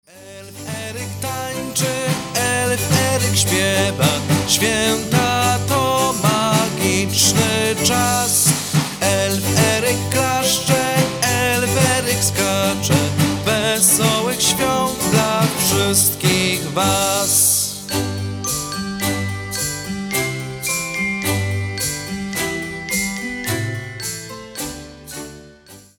Wesoła, świąteczna piosenka